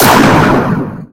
powerImplosionGrenade.wav